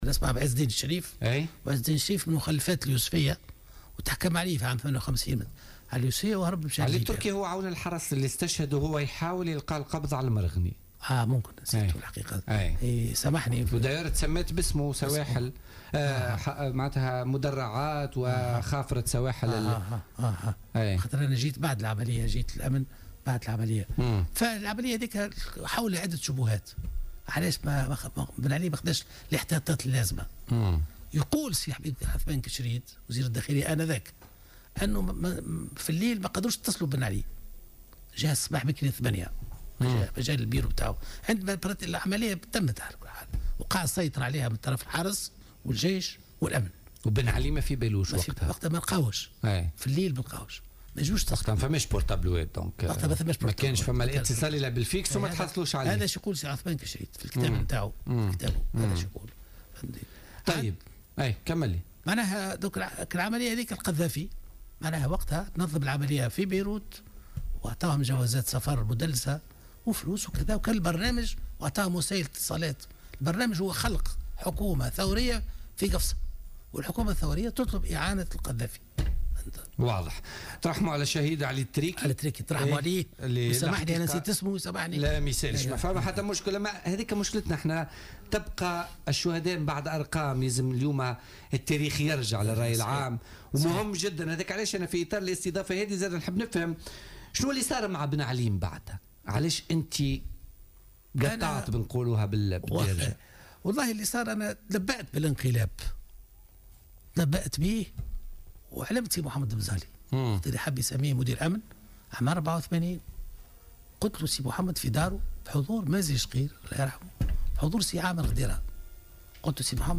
وأدلى بنور لـ "الجوهرة أف أم" في برنامج "بوليتيكا"، بشهادته حول المضايقات التي سلطها عليه بن علي قبل تسلمه مقاليد الحكم في "انقلاب 7 نوفمبر".